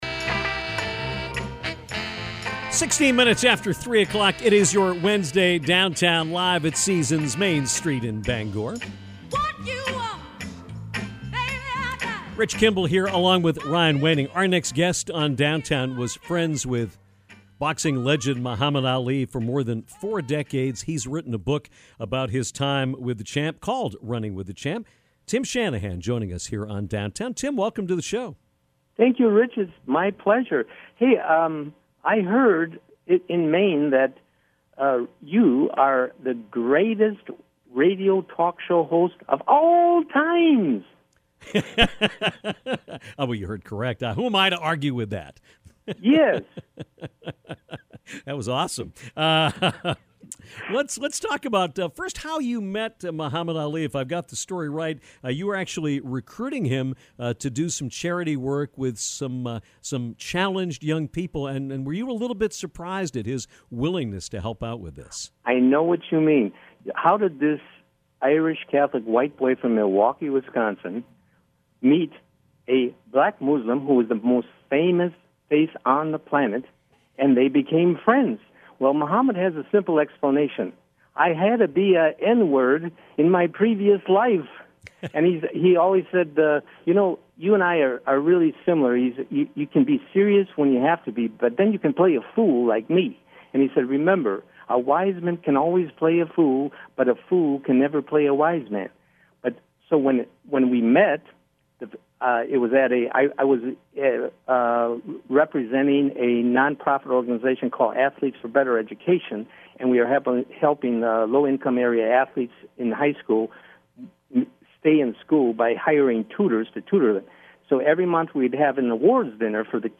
Things get emotional toward the end